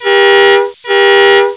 Sons urbanos 35 sons
buzina1.wav